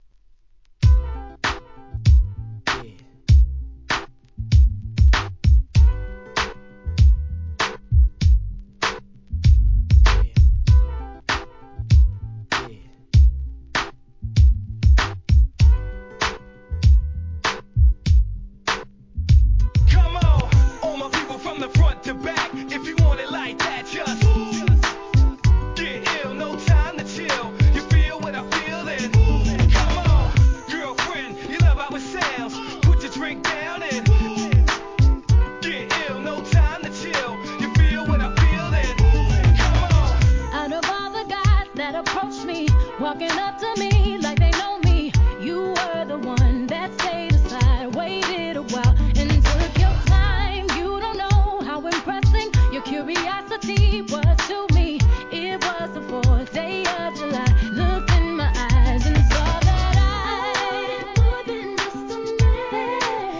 HIP HOP/R&B
REMIX, RE-EDITシリーズ第27弾!!